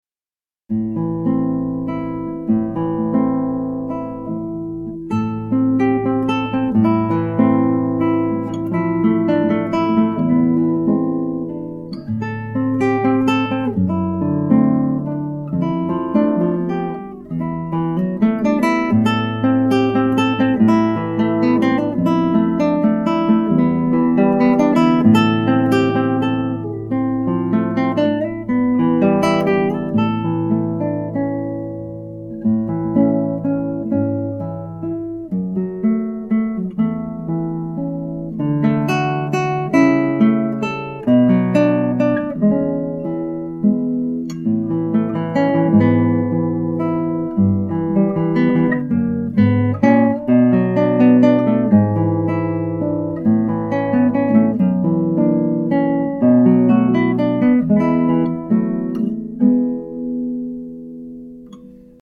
demo audio
c'est une jolie étude